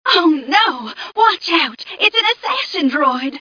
1 channel
mission_voice_m3ca005.mp3